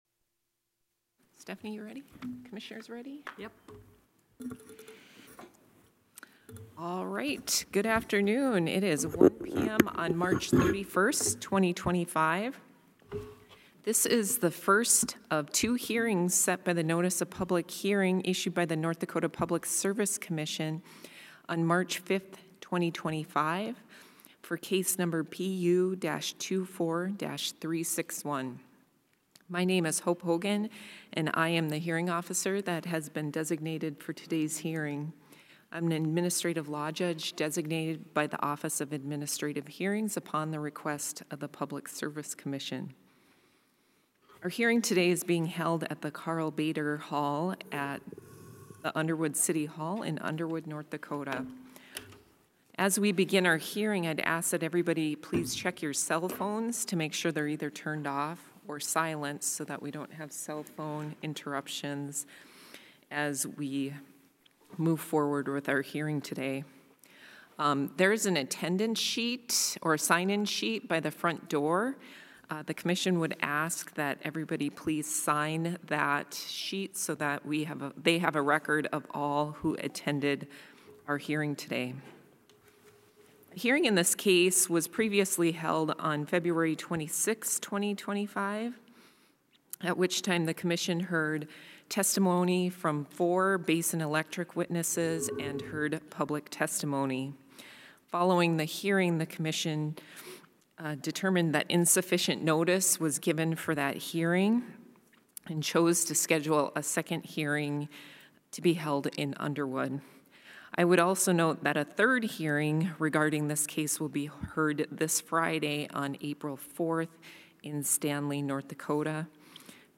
Electronic Recording of 31 March 2025 Formal Hearing